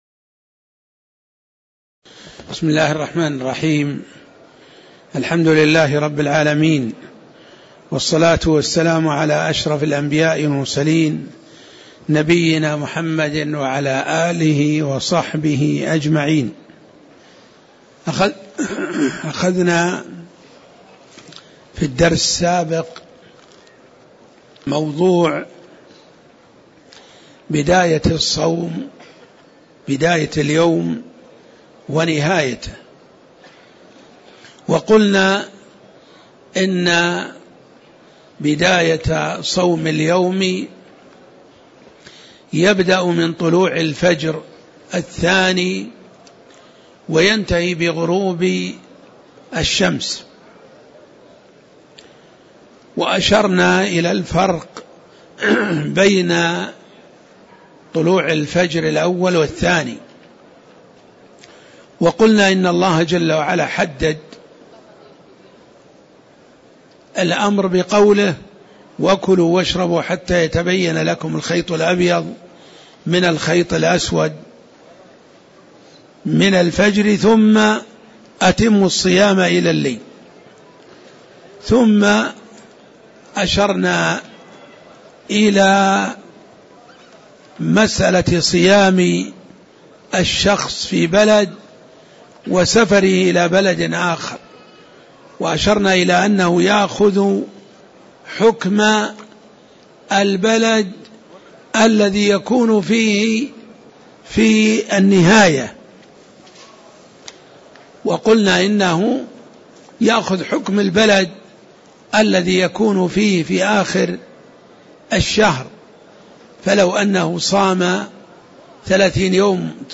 تاريخ النشر ٢٠ شعبان ١٤٣٧ هـ المكان: المسجد النبوي الشيخ